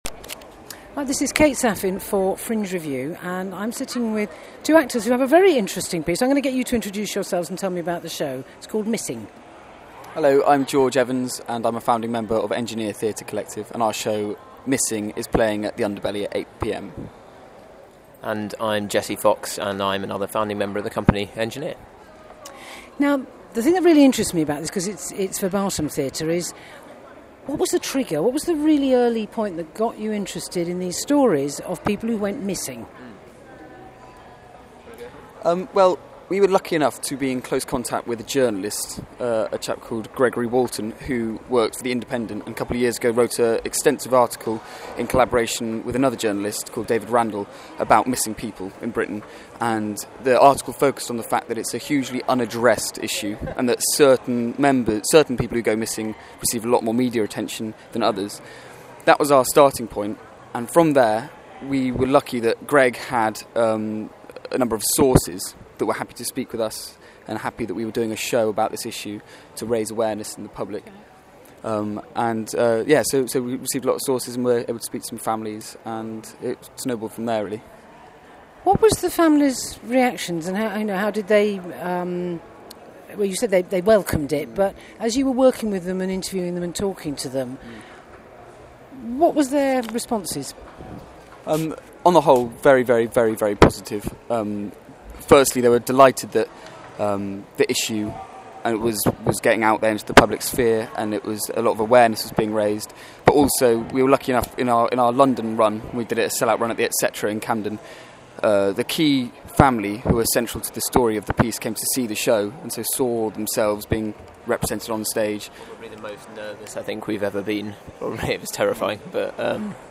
Audio Interviews, Edinburgh Festivals 2013, Edinburgh Fringe 2013